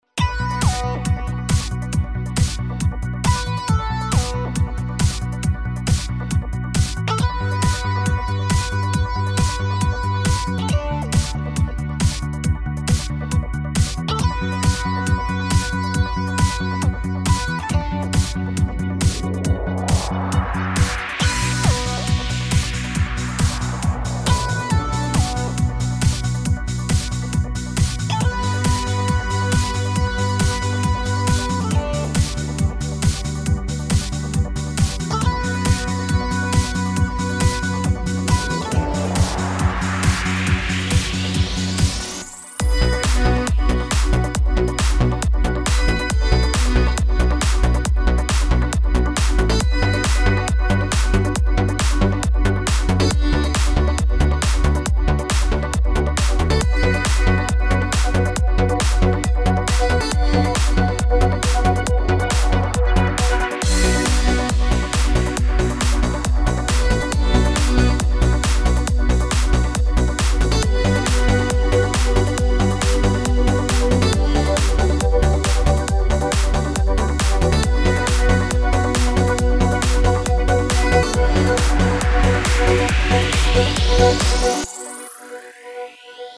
И главная проблема - синтез-гитара.
Наставник вроде бы свёл достойно, но гитара поменяна наспех (из-за моей нерасторопности мог бы и вовсе не сдать ко времени трек), поэтому она "тонкая", "режущая"; жирка бы ей, драйва и ещё совсем чуть-чуть гнусавости. Образец: первая часть - оригинал, вторая - сведённая с заменами.